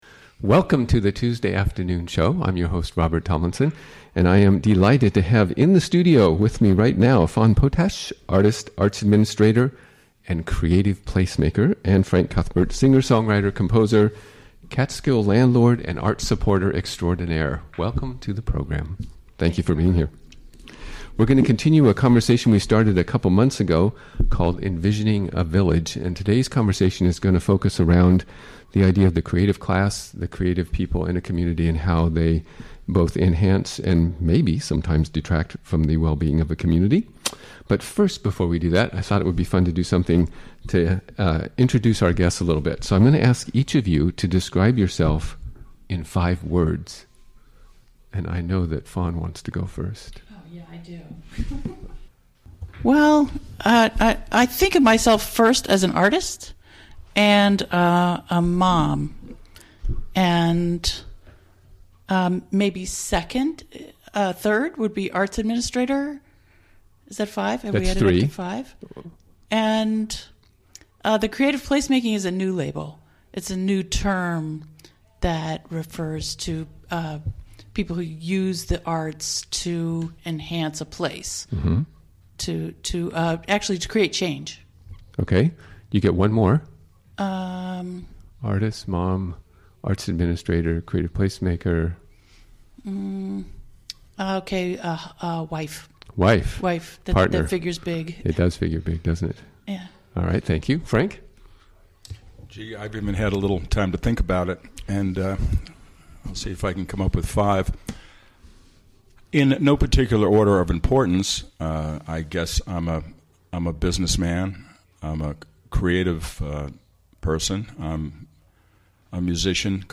Recorded live during the WGXC Afternoon Show of Tuesday, May 9, 2017.